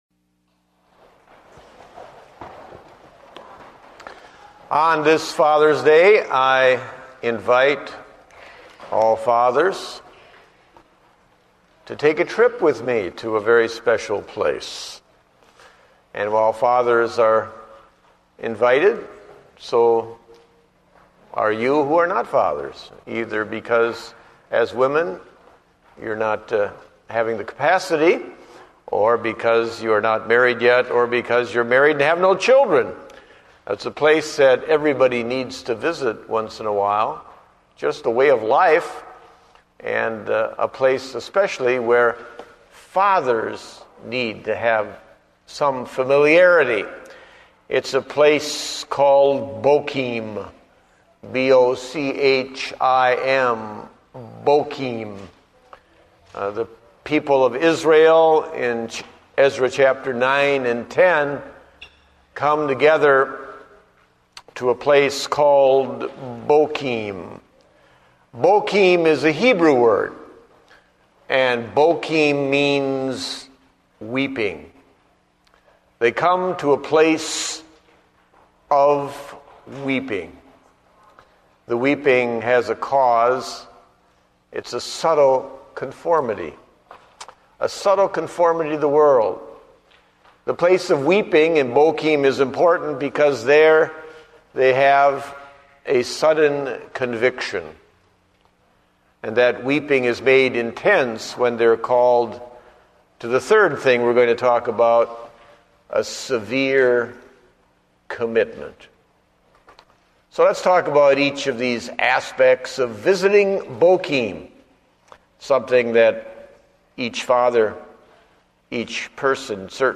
Date: June 21, 2009 (Morning Service)